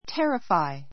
terrify A2 térəfai テ リふァイ 動詞 三単現 terrifies térəfaiz テ リふァイ ズ 過去形・過去分詞 terrified térəfaid テ リふァイ ド -ing形 terrifying térəfaiiŋ テ リふァイイン ぐ （身がすくむほど） おびえさせる, 怖 こわ がらせる terror Thunder terrifies our dog very much.